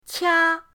qia1.mp3